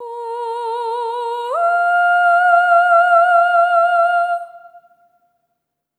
SOP5TH A#4-R.wav